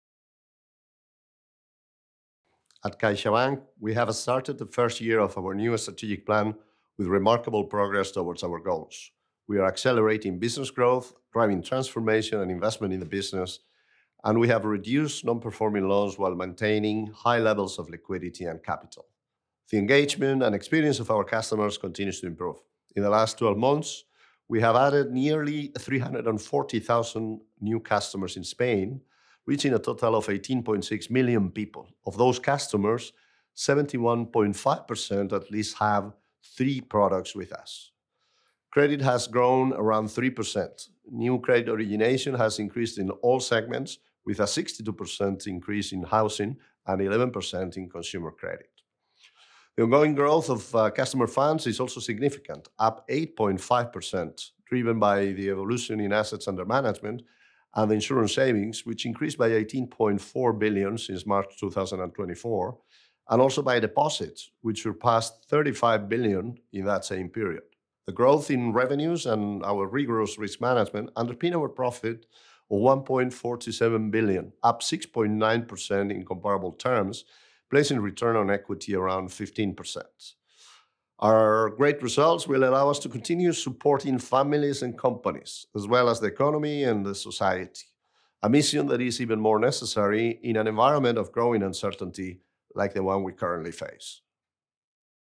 Quote from CaixaBank's CEO, Gonzalo Gortázar